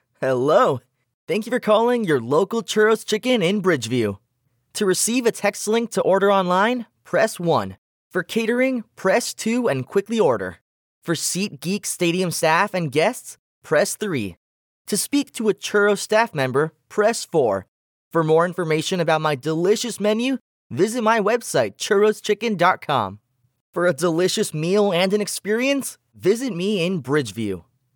Telephone/IVR
Telephone/IVR Sample